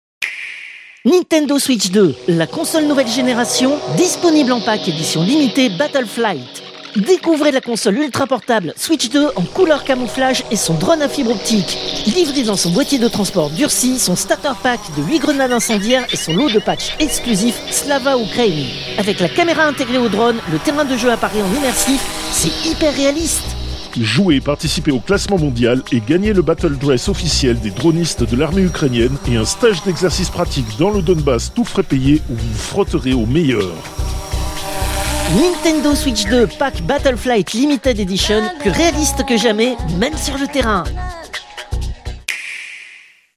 Fausse publicité : Nintendo Switch 2 Battleflight edition
Extrait de l'émission CPU release Ex0222 : lost + found (rentrée 2025) seconde partie).